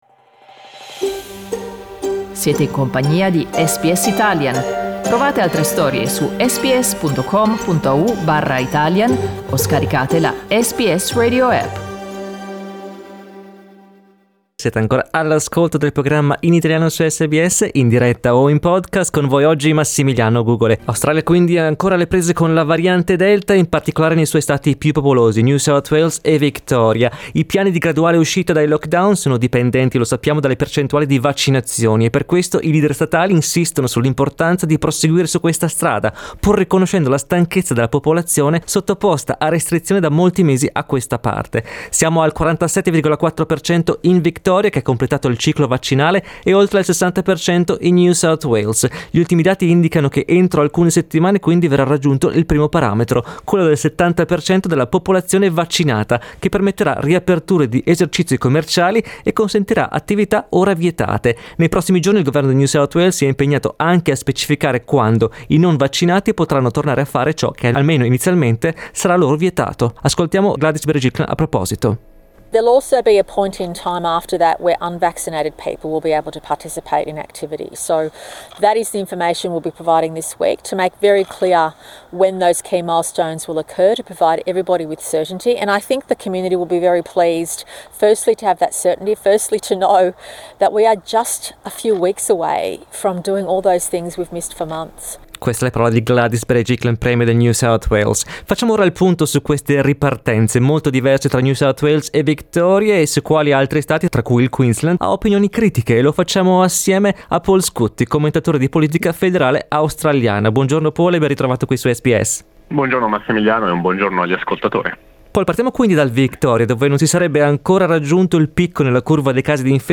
il commentatore di politica federale